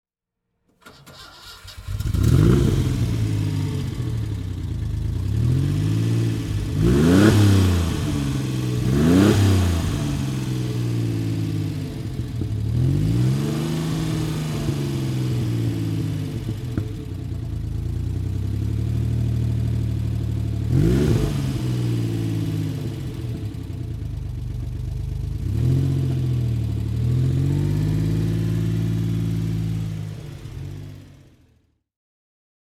Citroën SM Mylord (1973) - Starten und Leerlauf
Citroen_SM_Mylord_1973.mp3